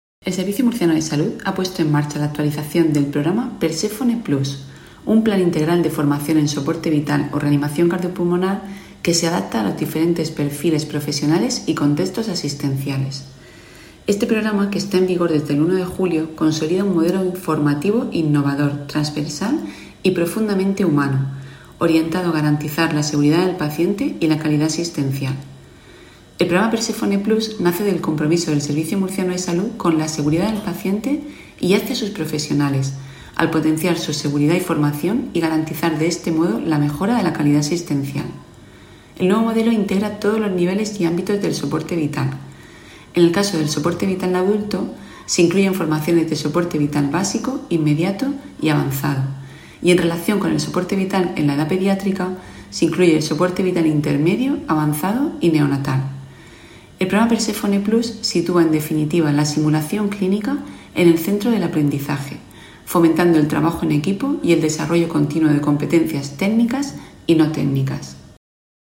Sonido/ Declaraciones de la gerente del SMS, Isabel Ayala, sobre el nuevo programa formativo en reanimación cardiopulmonar.
La presentación del programa en reanimación cardiopulmonar se realizó en el hospital Morales Meseguer.